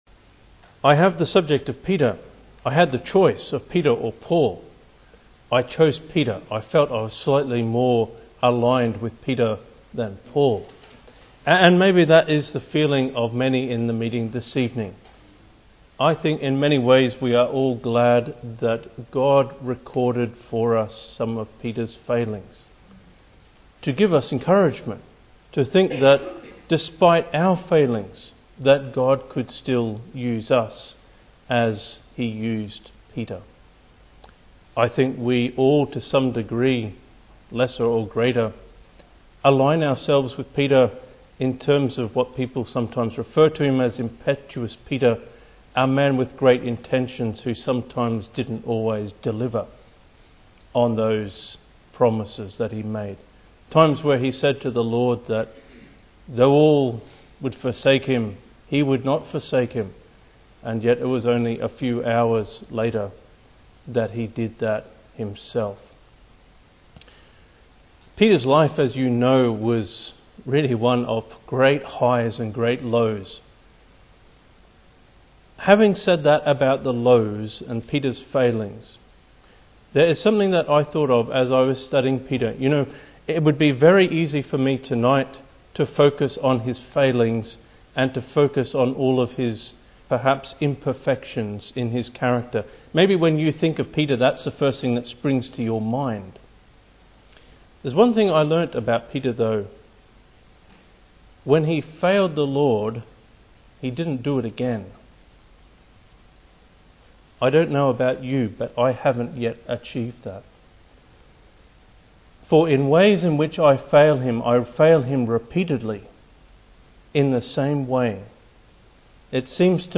He makes the challenging point that, although Peter is often remembered for his failures, he was quickly restored and didn’t repeat his mistakes (Message preached 28th May 2015)